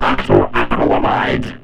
145 Kb Vocoded voice saying "Octyl Acrylamide"